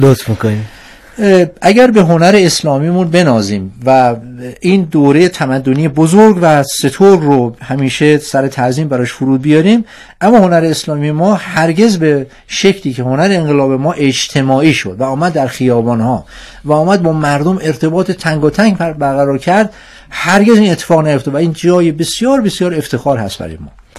میزگرد آسیب‌شناسی هنر نقاشی انقلاب اسلامی/ 2